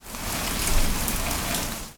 rain_3.ogg